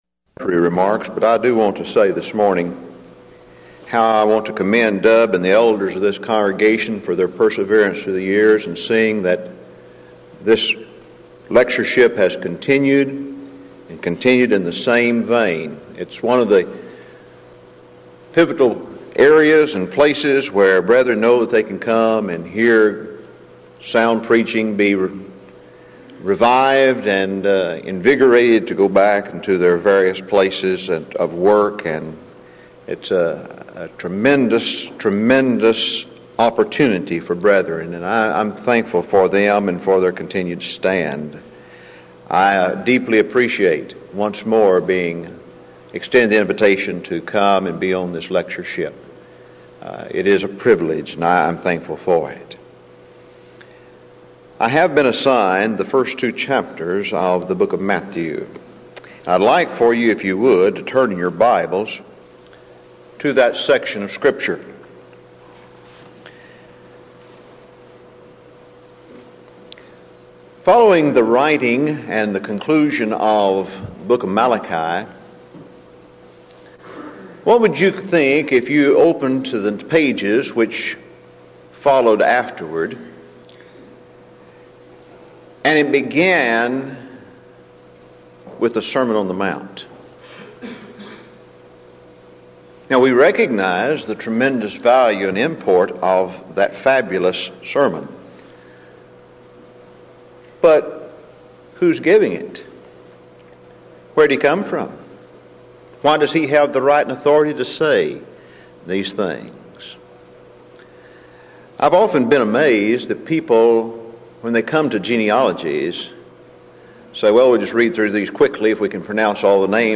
Event: 1995 Denton Lectures